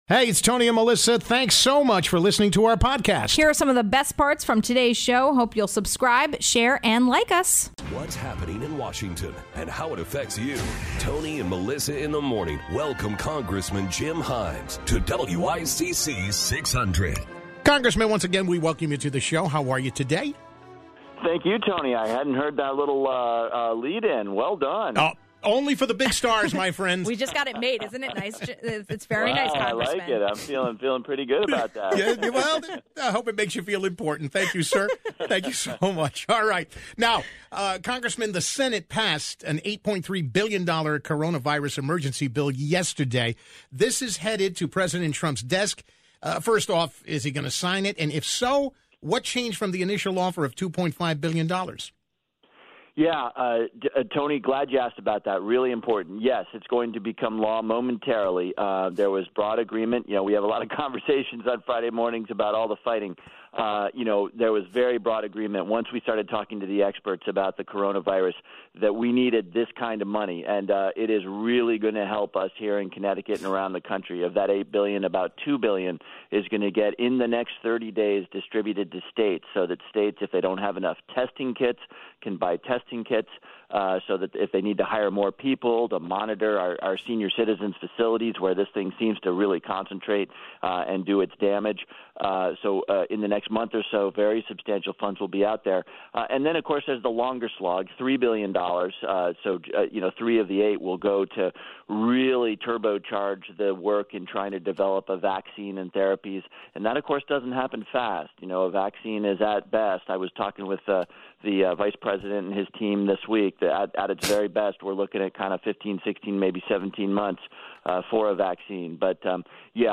1. Congressman Jim Himes addresses sanitizing on public transportation and its future. ((00:08))